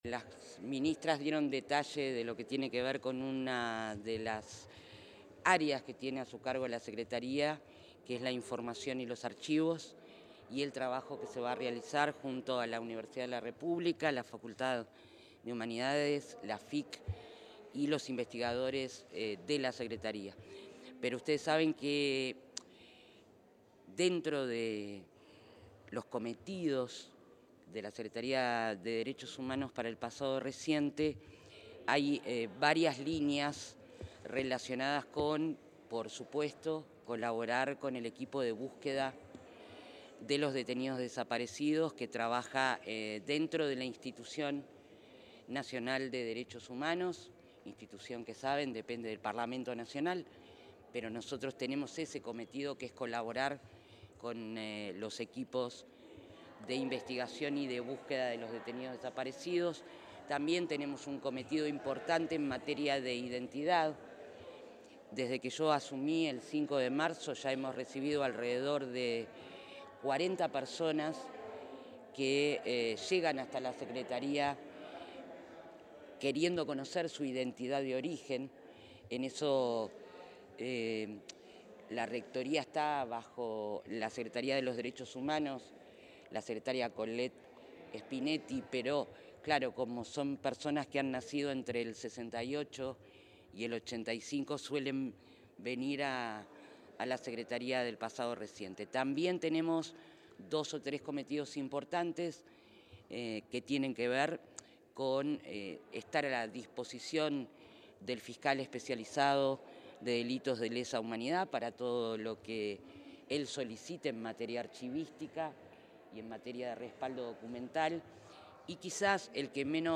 Declaraciones de la directora de la Secretaría de Derechos Humanos para el Pasado Reciente, Alejandra Casablanca
La directora de la Secretaría de Derechos Humanos para el Pasado Reciente, Alejandra Casablanca, dialogó con la prensa tras la presentación de los
casablanca_prensa.mp3